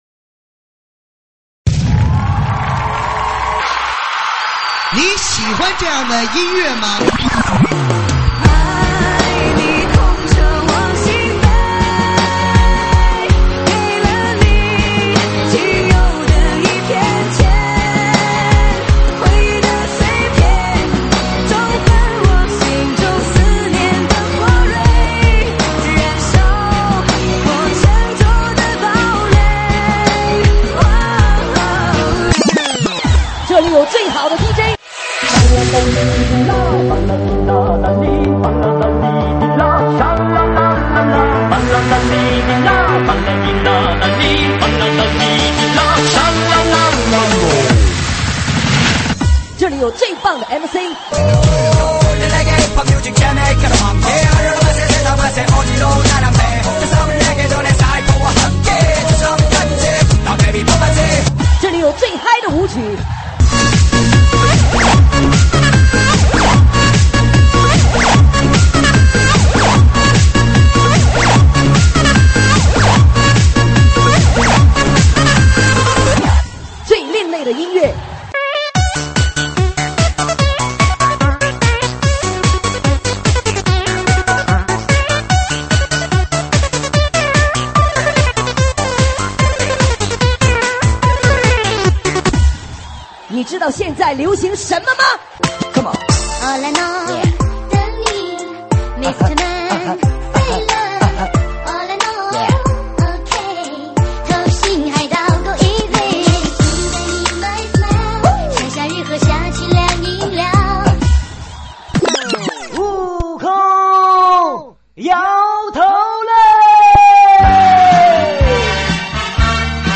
伤感情歌 暖场